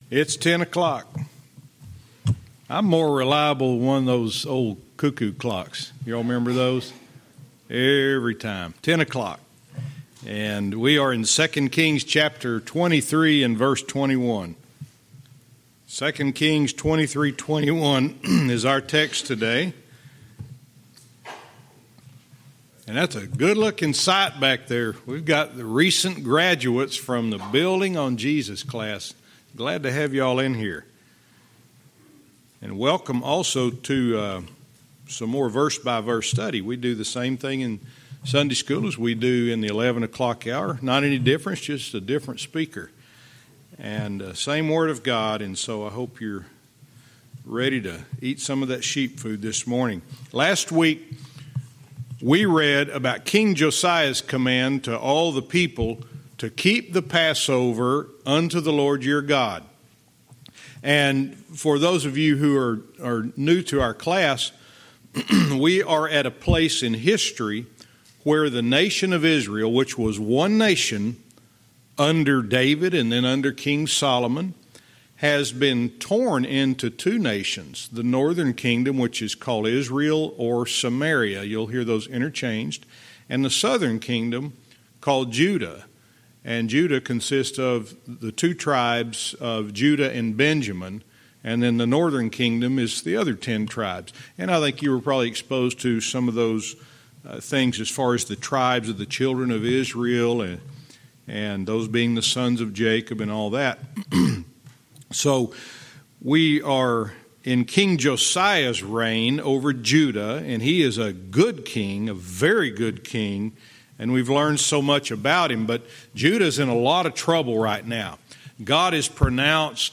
Verse by verse teaching - 2 Kings 23:21-26